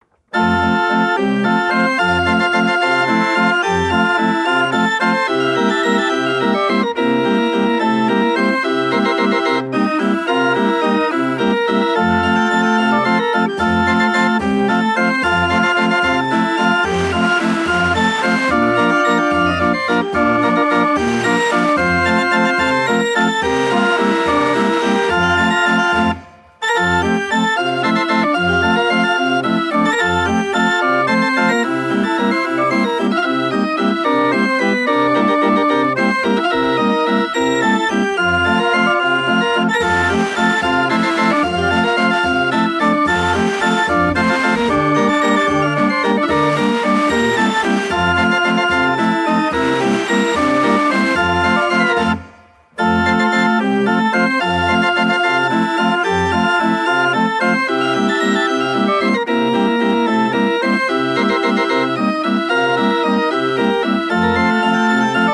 BAND ORGANS/FAIR ORGANS